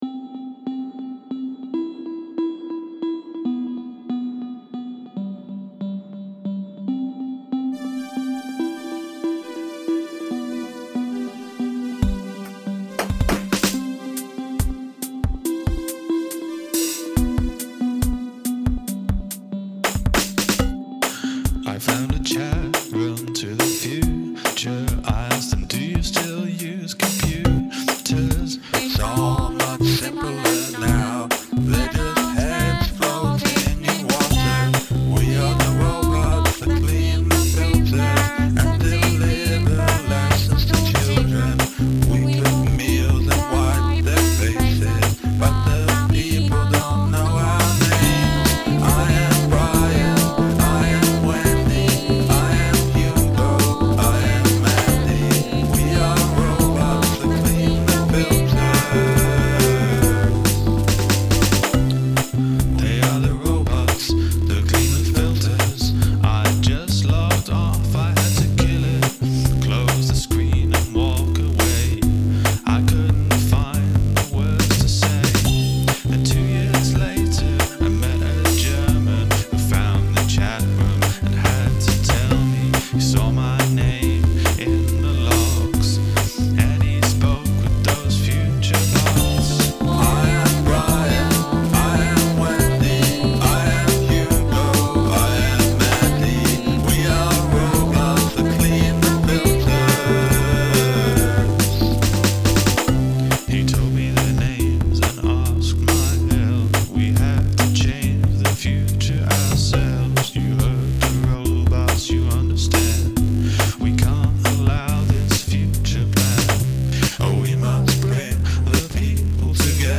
verse: C E B G
chorus: E F G A (rising)
The overall vibe is dystopian ridiculousness, but it's fun.